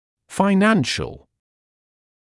[faɪ’nænʃl][фай’нэншнл]финансовый